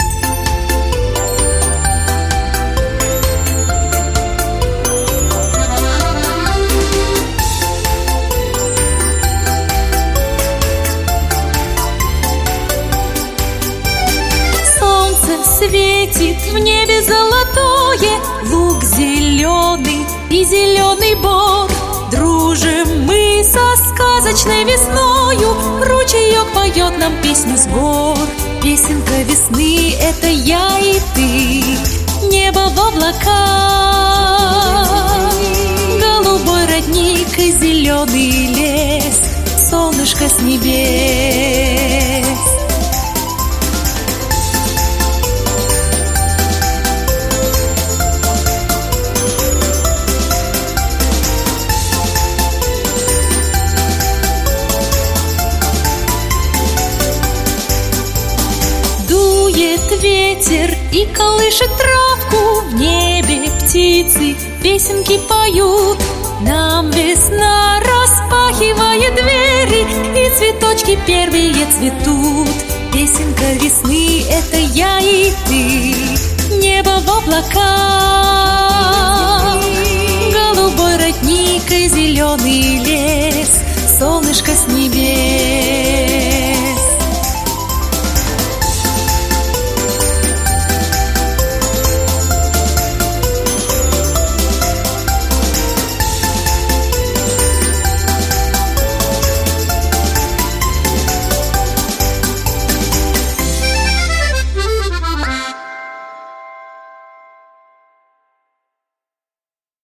Песенки про весну